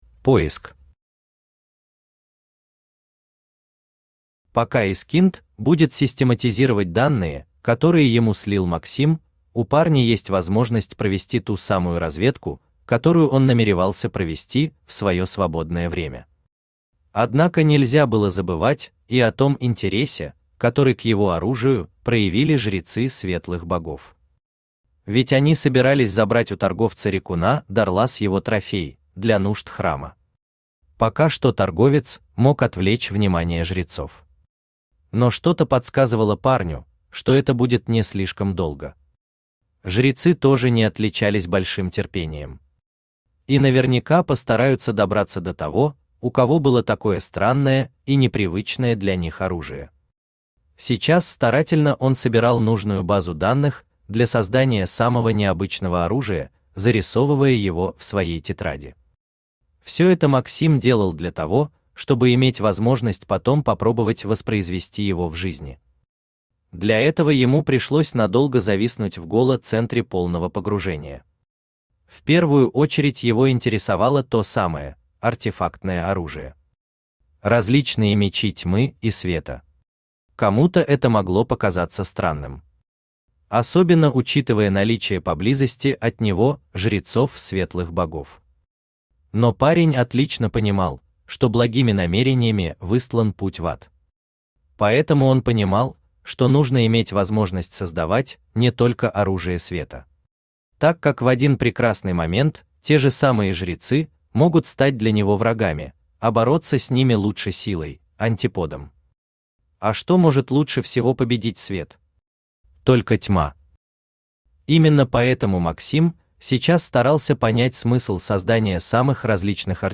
Аудиокнига Колонист. Часть 3. Способность выживать | Библиотека аудиокниг
Способность выживать Автор Хайдарали Усманов Читает аудиокнигу Авточтец ЛитРес.